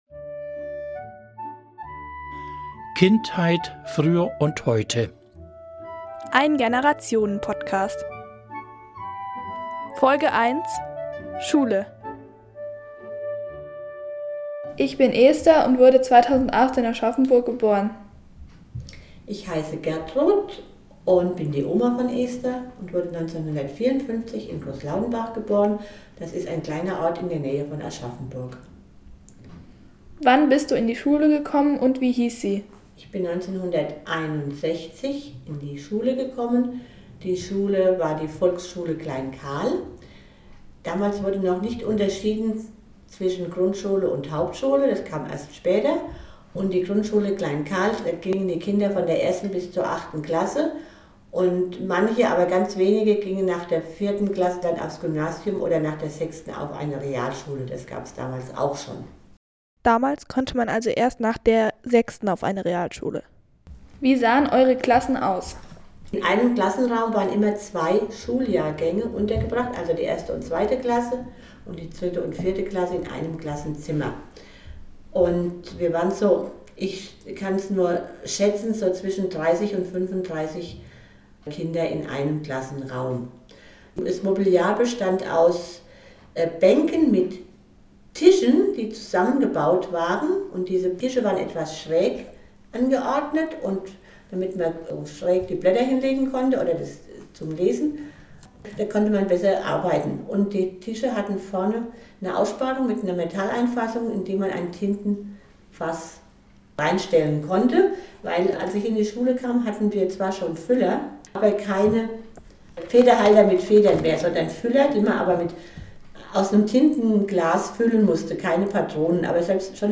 Ulmer Radio von Kindern für Kinder